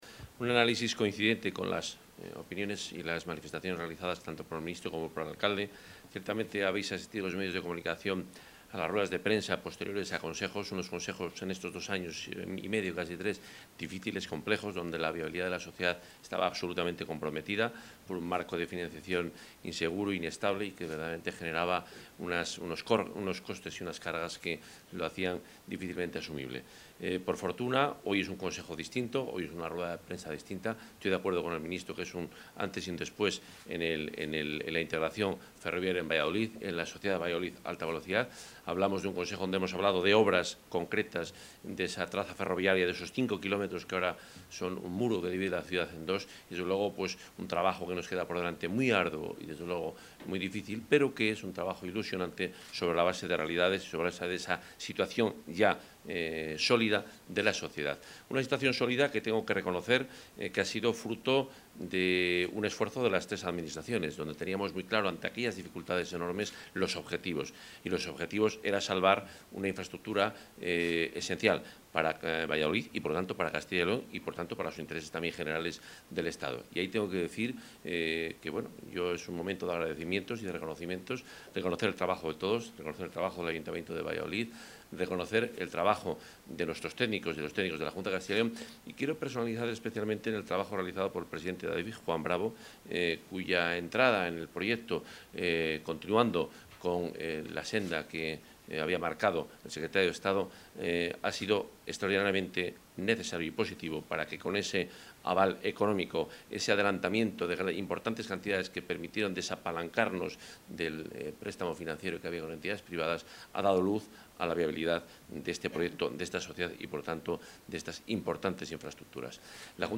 Intervención del consejero de Fomento y Medio Ambiente.
El consejero de Fomento y Medio Ambiente, Juan Carlos Suárez-Quiñones, ha asistido hoy a la reunión del consejo de administración de la sociedad Valladolid Alta Velocidad.